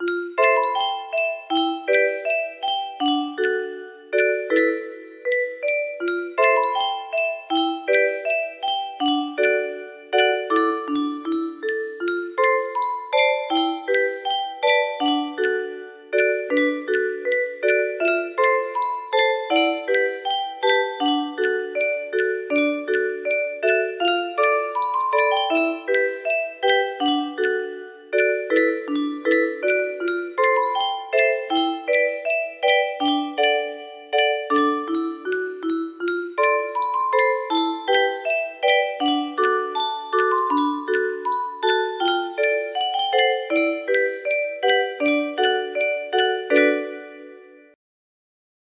clean ending version slowed down